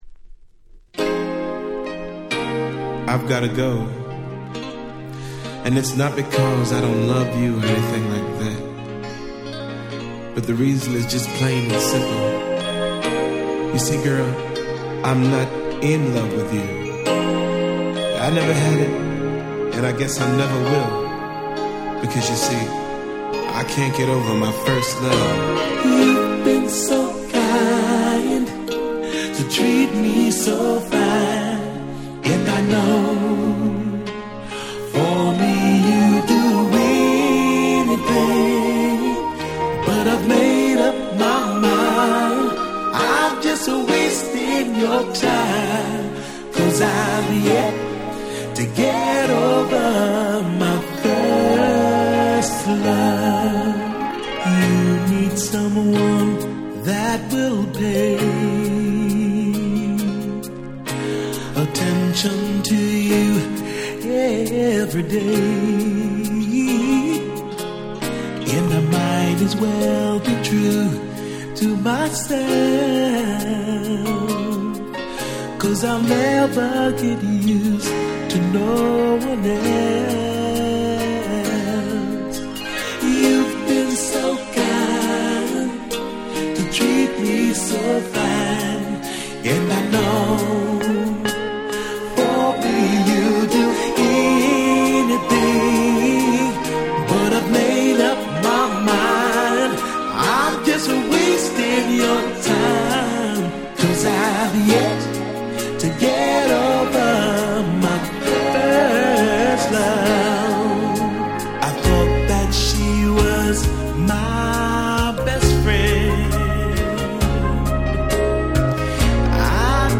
89' Smash Hit R&B / Slow Jam / Ballad !!